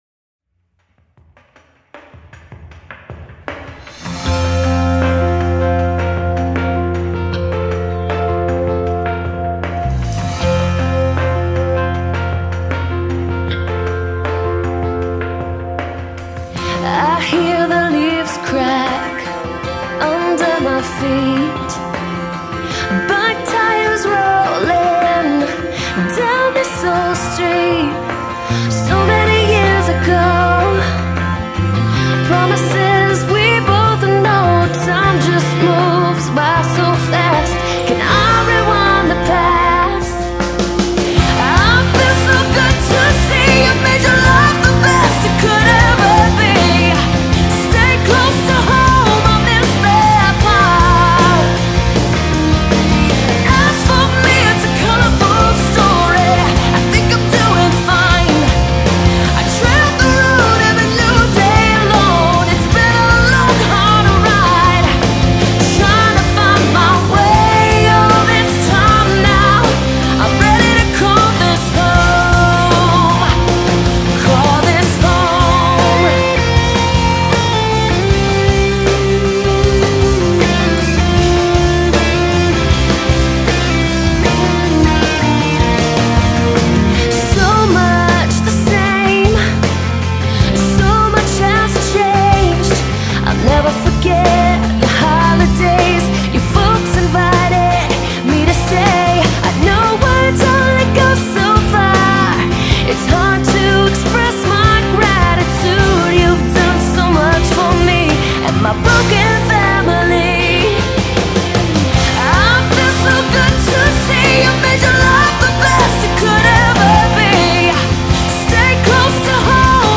a rock/pop songwriter/guitarist based in Redondo Beach
‘Call This Home’ is a melodic, driving uptempo song, which has a heartfelt lyric theme about wanting to return home.
this recording features impressive lead & harmony vocals by Los Angeles-based artist